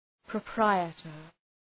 Προφορά
{prə’praıətər}